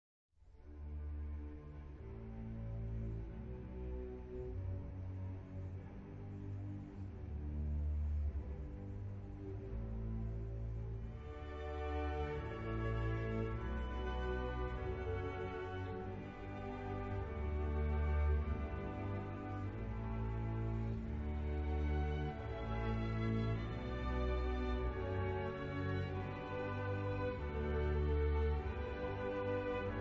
• Stuttgarter Kammerorchester [interprete]
• Munchinger, Karl [direttore d'orchestra]
• musica classica
• Música clássica